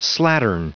Prononciation du mot slattern en anglais (fichier audio)
Prononciation du mot : slattern